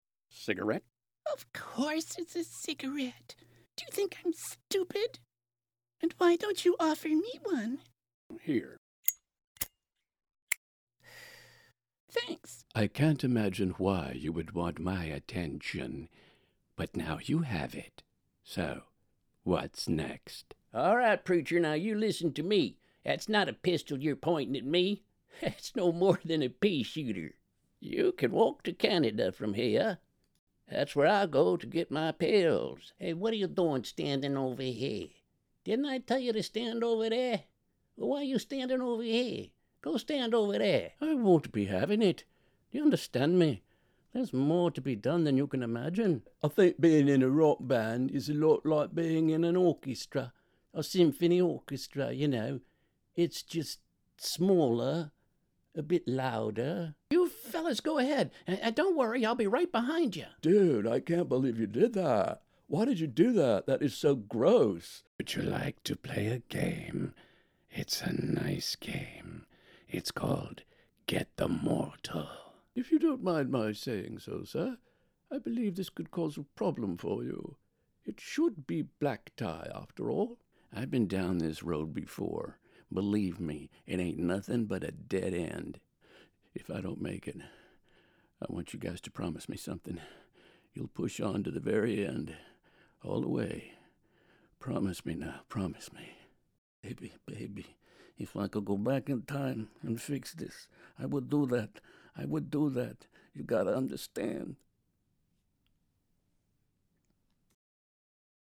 Voice Demos